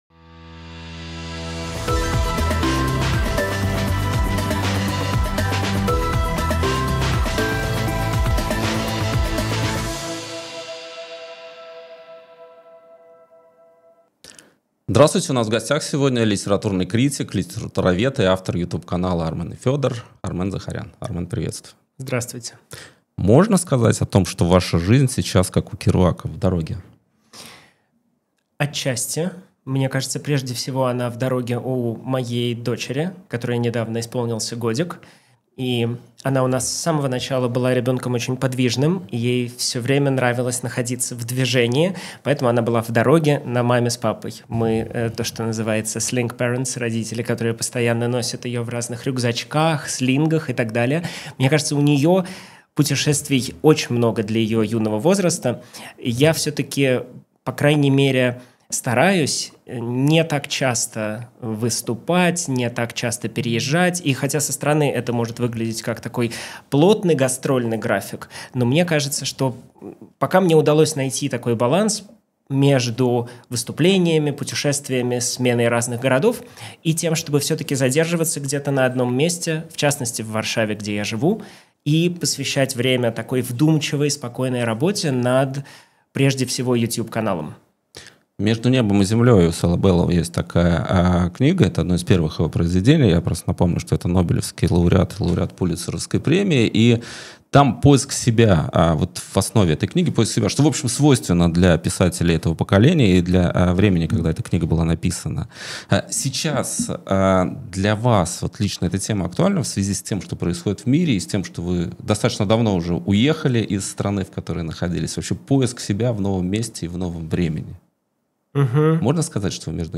zaharyan-etot-final-ledenit-dushu.-no-mozhet-byt-inache.-intervyu-kotoroe-ne-stoit-propustit.mp3